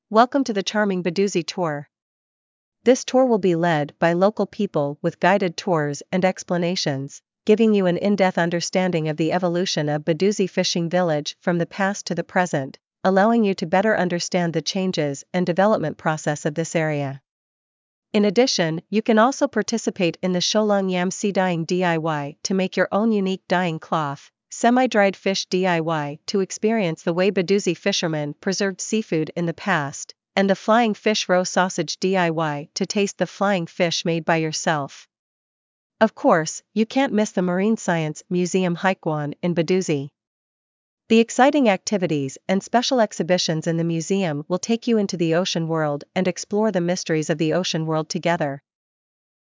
One-Minute Free Trial of This Route's Audio Guide